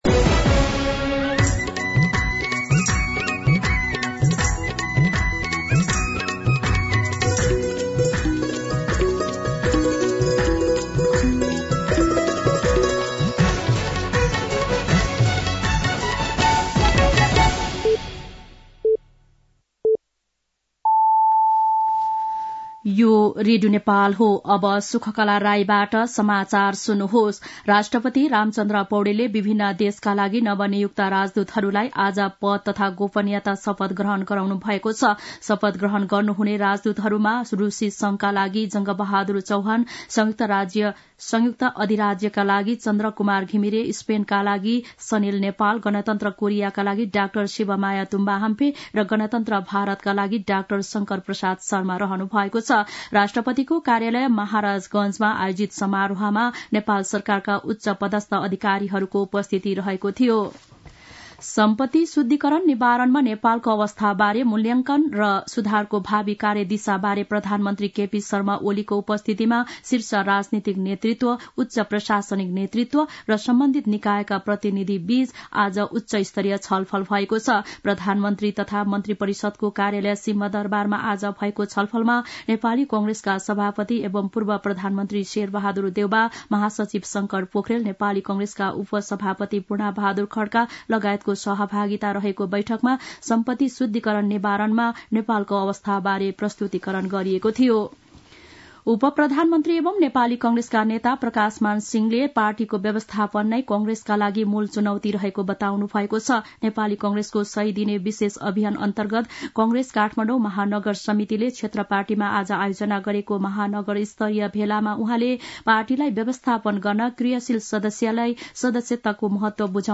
दिउँसो ४ बजेको नेपाली समाचार : ९ पुष , २०८१
4pm-nepali-news-1-1.mp3